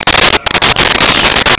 Sfx Whoosh 4501
sfx_whoosh_4501.wav